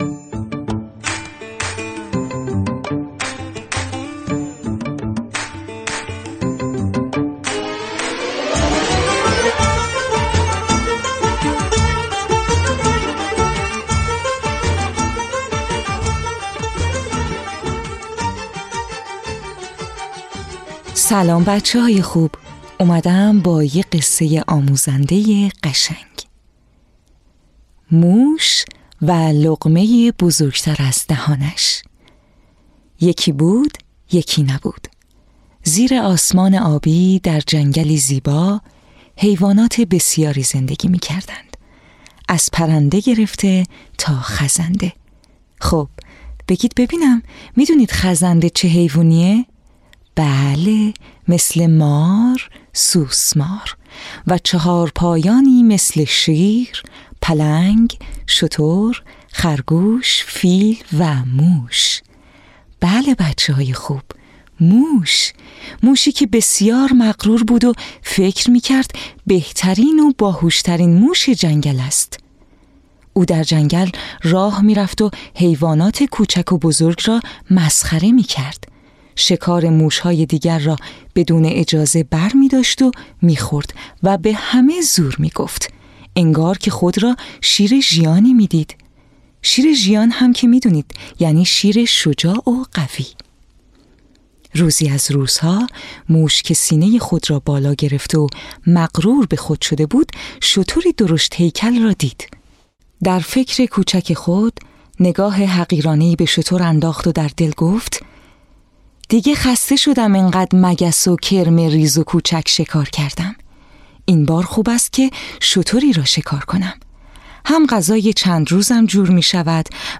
قصه های کودکانه صوتی - این داستان: موش و لقمه ی بزرگ تر از دهانش
تهیه شده در استودیو نت به نت